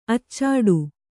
♪ accāḍu